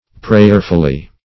-- Prayer"ful*ly , adv.